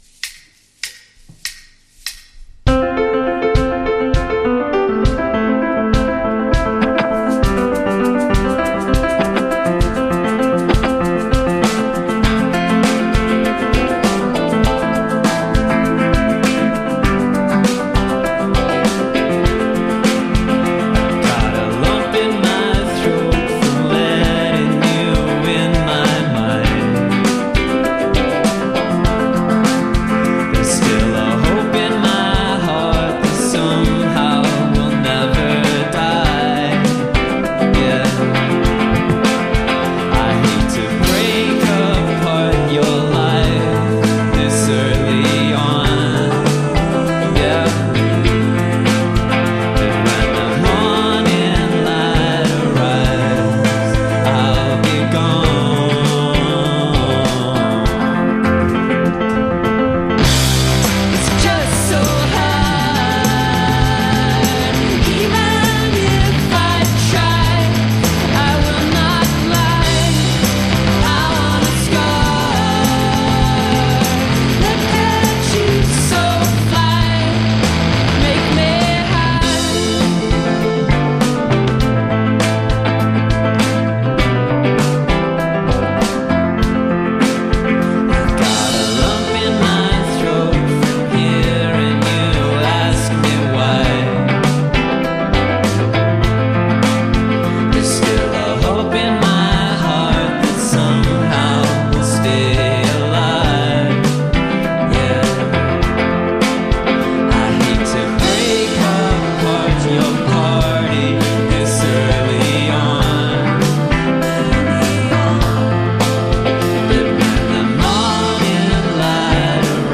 Indie from Australia
indie rock band